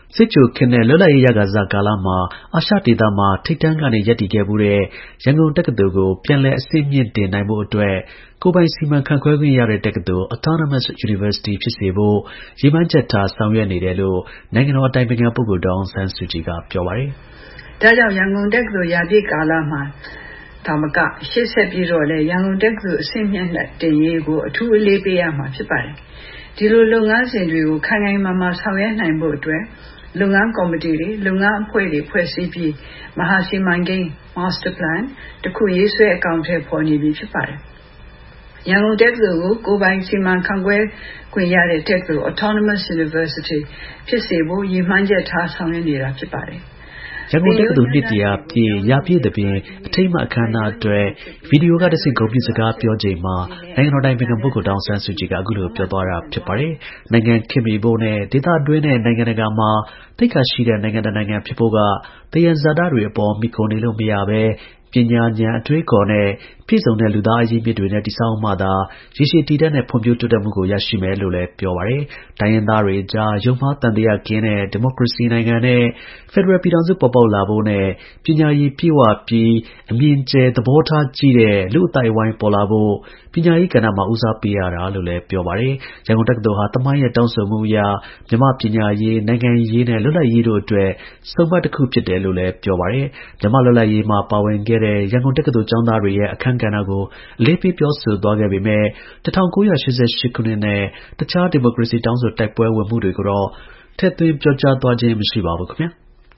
၂၀၂၀ ပြည့်နှစ်၊ ဒီဇင်ဘာလ ၁ ရက်နေ့မှာကျရောက်တဲ့ ရန်ကုန်တက္ကသိုလ် နှစ် ၁၀၀ ပြည့် ရာပြည့်သဘင် အထိမ်းအမှတ် အခမ်းအနား အတွက် ဗီဒီယိုကတဆင့် ဂုဏ်ပြုစကား ပြောကြားချိန်မှာ နိုင်ငံတော် အတိုင်ပင်ခံ ပုဂ္ဂိုလ် ဒေါ်အောင်ဆန်းစုကြည်က အခုလို ပြောသွား တာ ဖြစ်ပါတယ်။